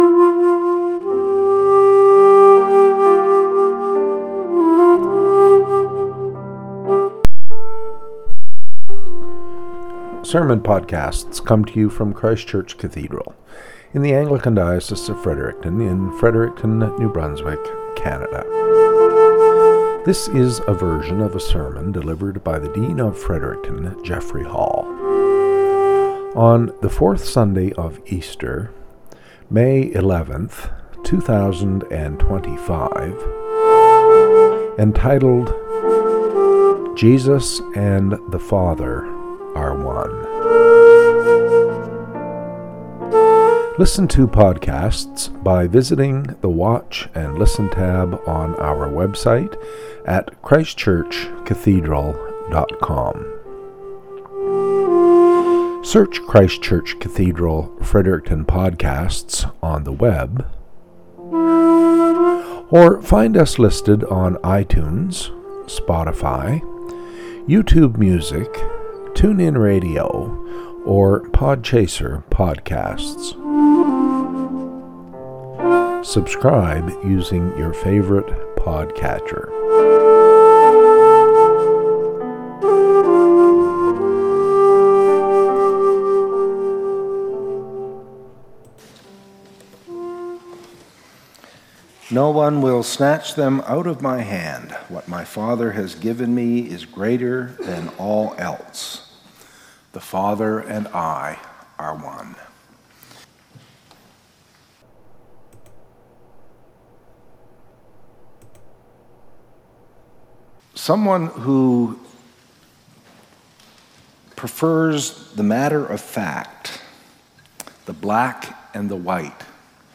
Cathedral Podcast - SERMON -